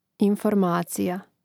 informácija informacija